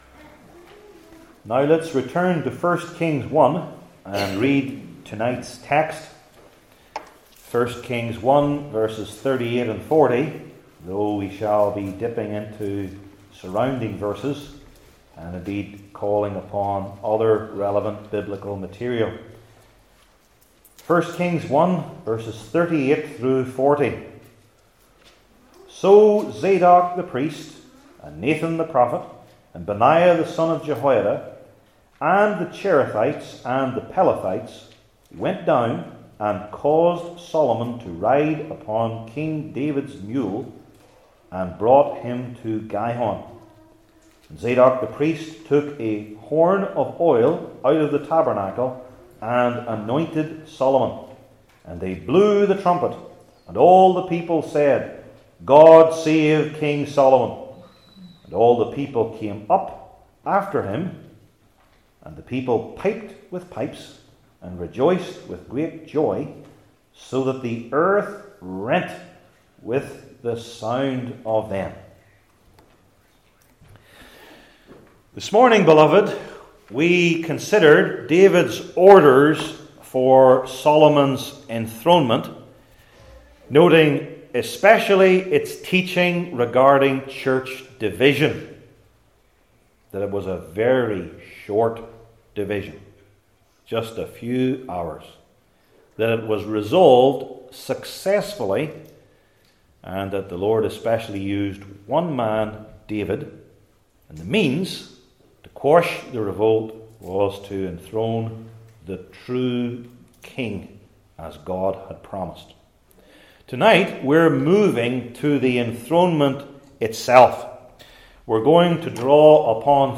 Service Type: Old Testament Sermon Series